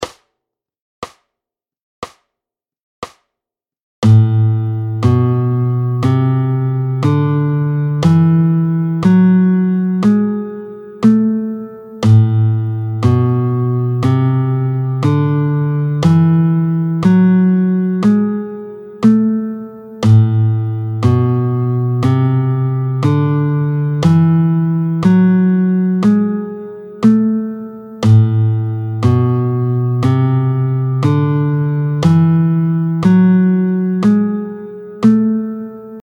24-02 La mineur mélodique, tempo 60